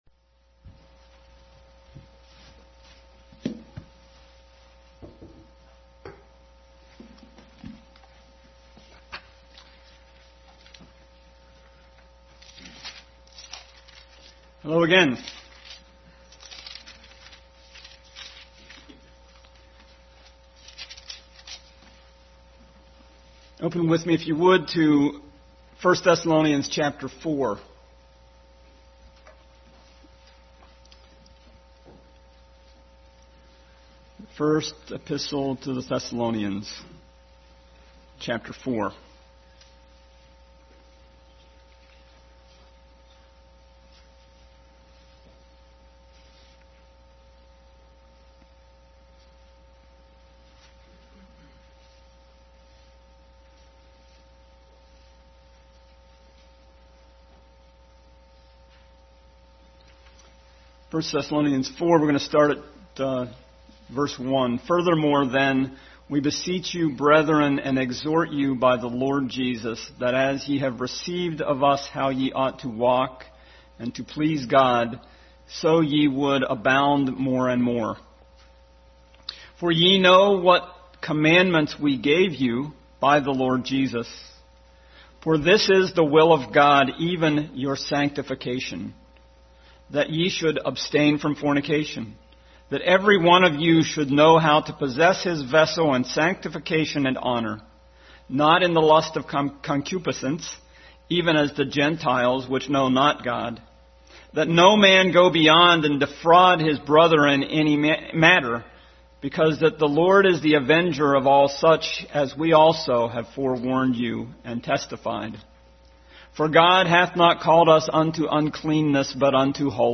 Bible Text: 1 Thessalonians 4 | Family Bible Hour Message.